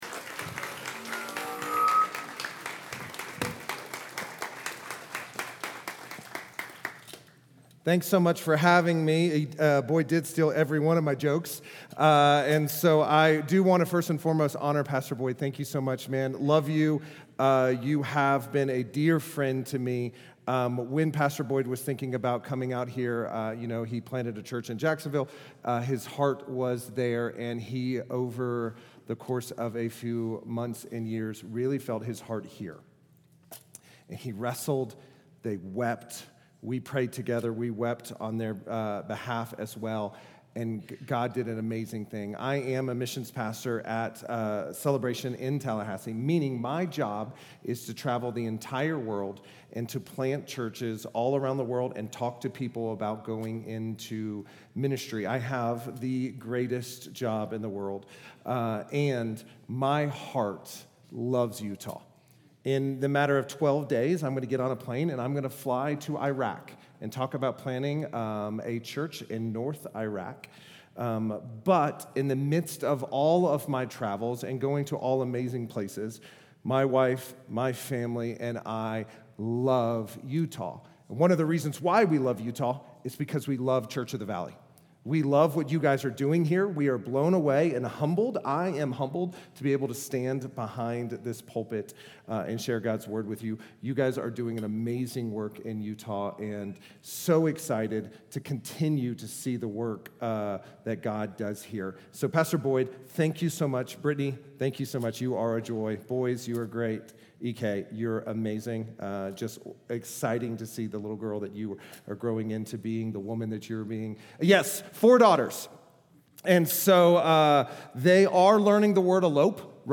New Year's Sermon 2026